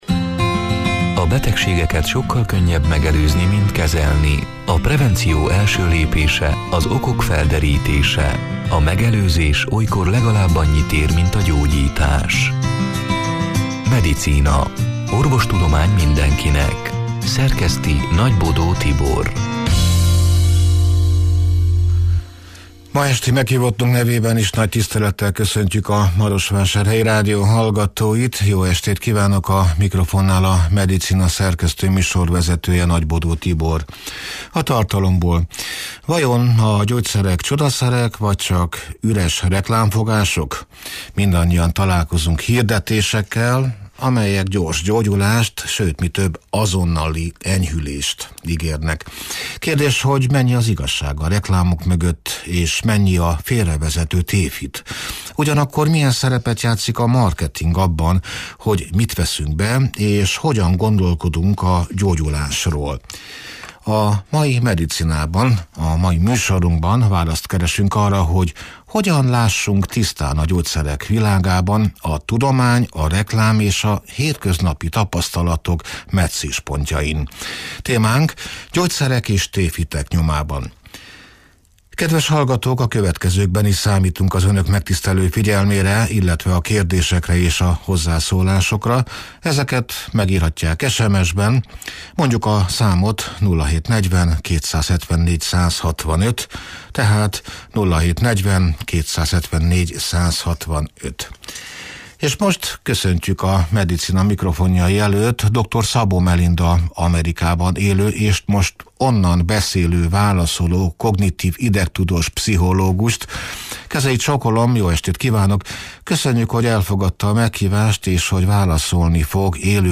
beszélget